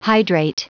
Prononciation du mot hydrate en anglais (fichier audio)
Prononciation du mot : hydrate
hydrate.wav